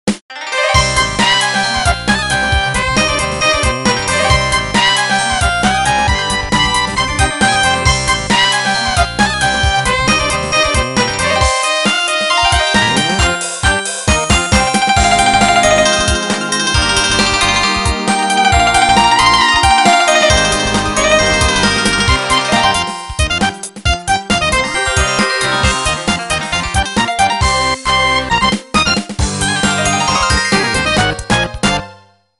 Kategori: Nada dering